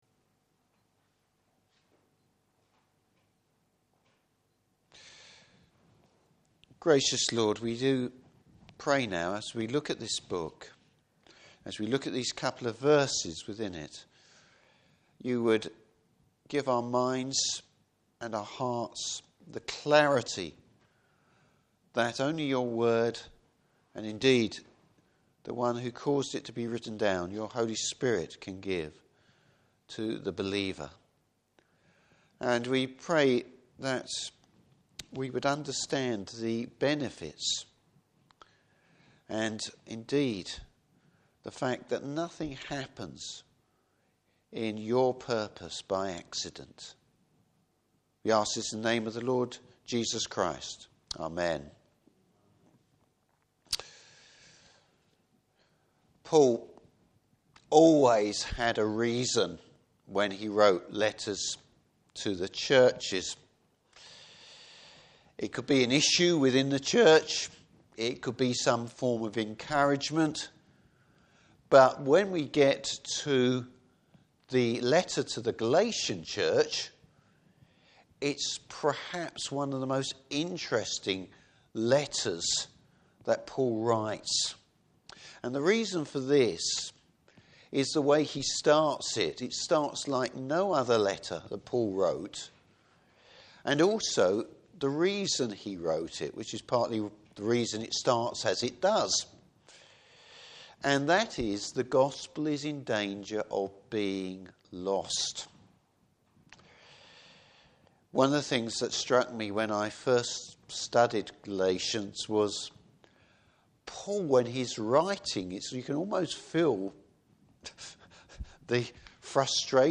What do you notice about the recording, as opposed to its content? Service Type: Morning Service There’s no accidents in God’s plan.